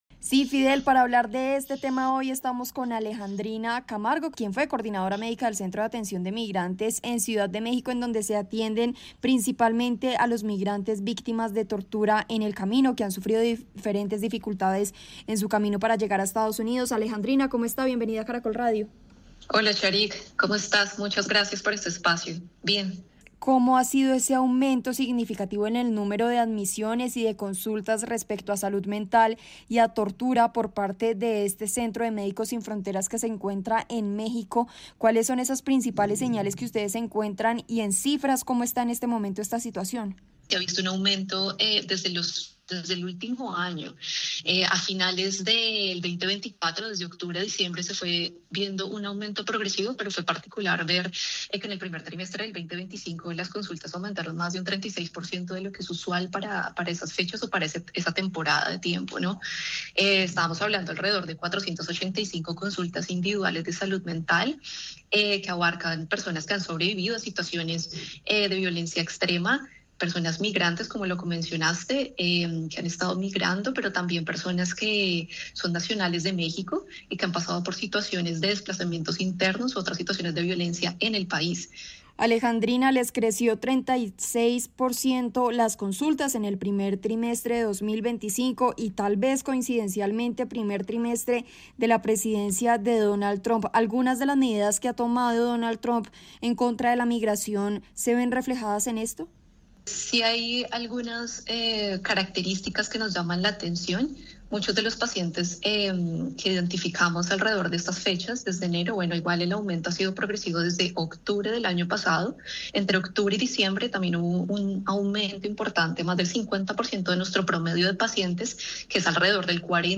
en entrevista con Caracol Radio que el incremento de atenciones inició hacia finales de 2024